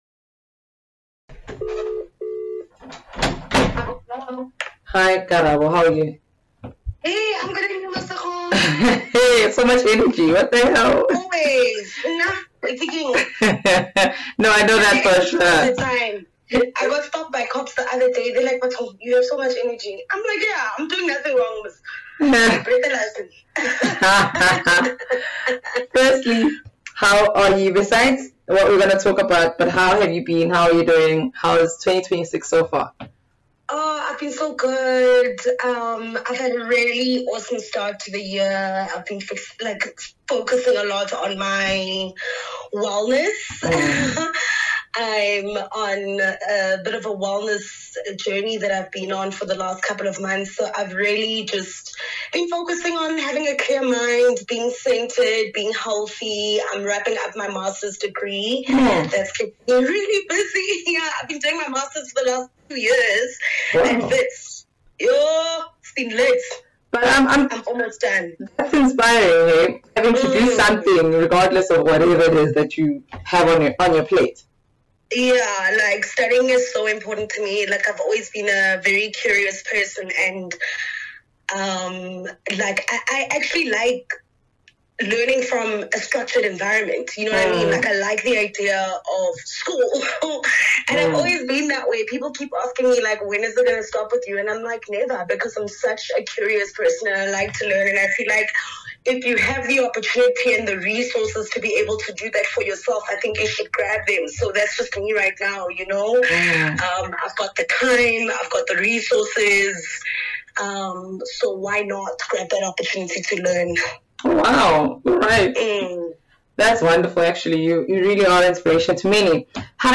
The seasoned broadcaster is transitioning from night-time radio to host 5 Mid Mornings with Karabo, where she promises listeners nothing but “pockets of joy” between 9am and 12pm. Ntshweng talks to Sowetan about this career-defining shift, sharing how the new chapter reflects both her personal growth and her evolving voice in the entertainment industry.